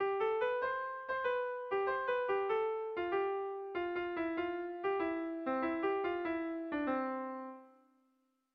Etorri nintzanean I - Bertso melodies - BDB.
Erromantzea
AB